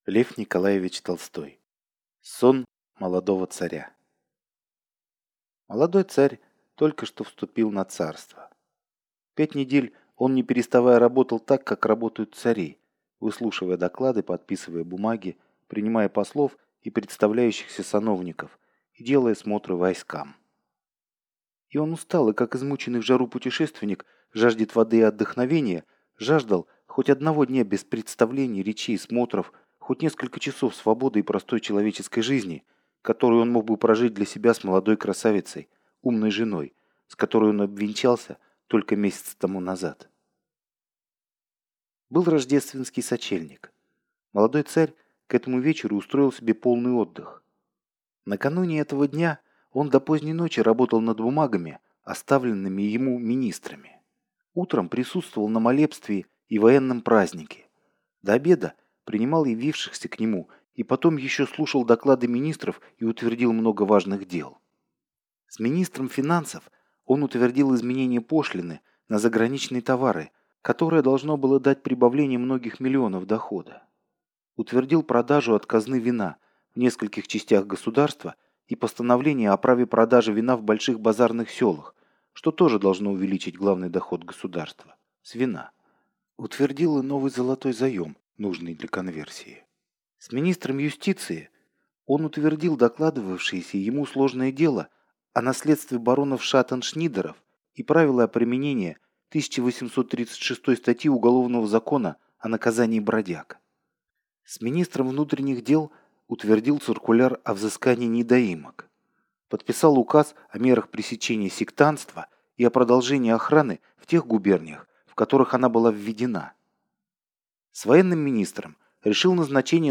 Аудиокнига Сон молодого царя | Библиотека аудиокниг
Aудиокнига Сон молодого царя Автор Лев Толстой Читает аудиокнигу Алгебра Слова.